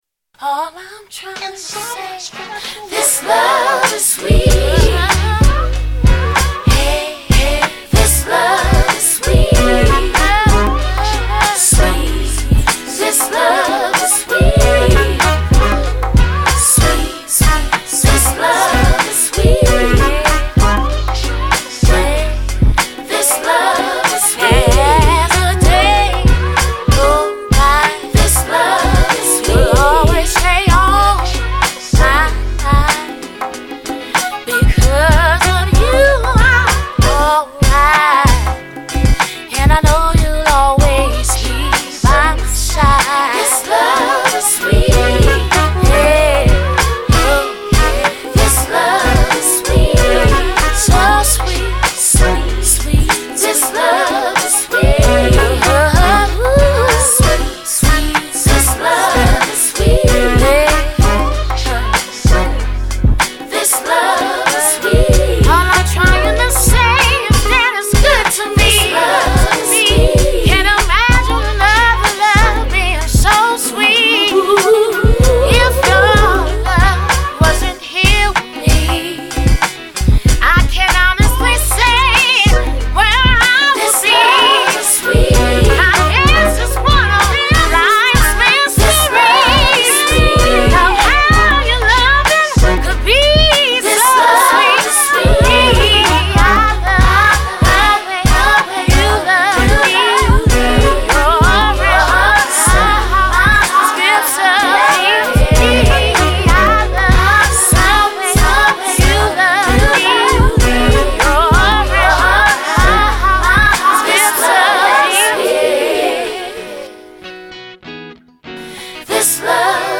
is a throwback soul jam